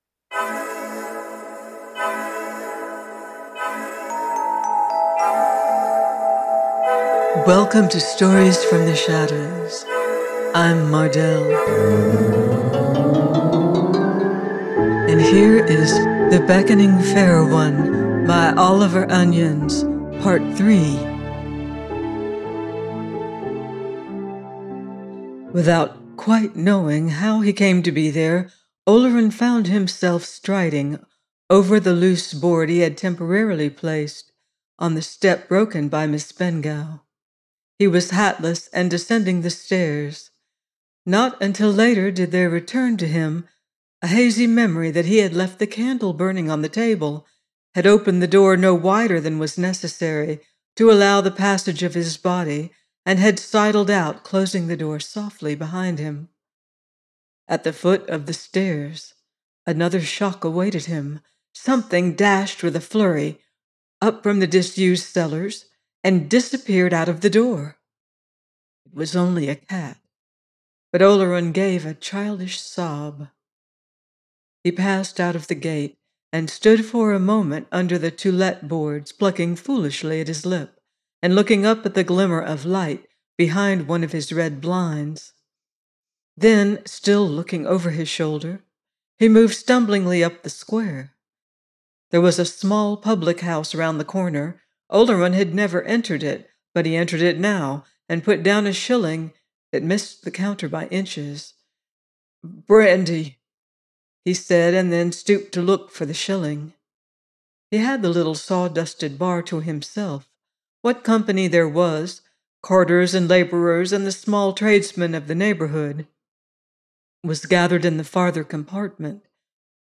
The Beckoning Fair One – Oliver Onions - audiobook